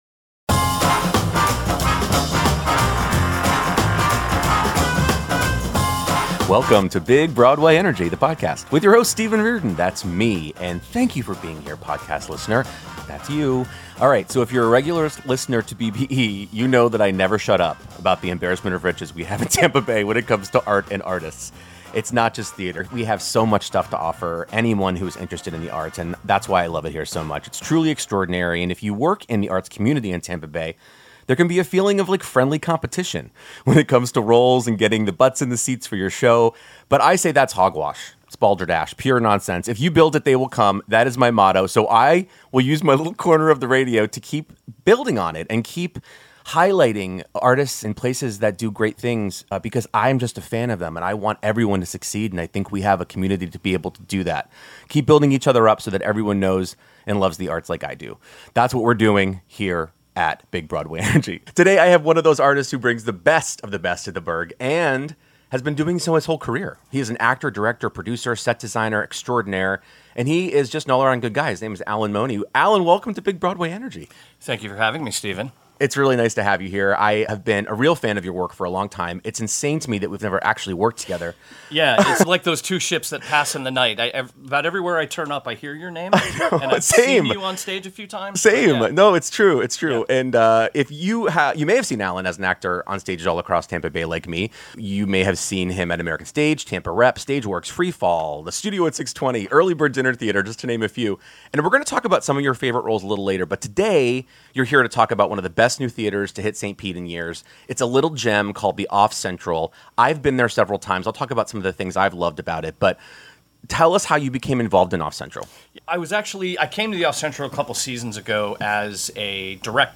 Whether you're a local arts lover or a national theater fan, this conversation is a heartfelt reminder of why art isn't a nice-to-have—it's a must-have.